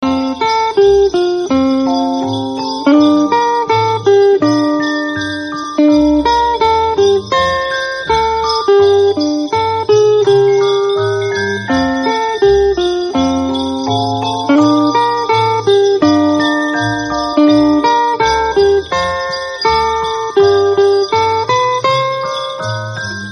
Categoria Natale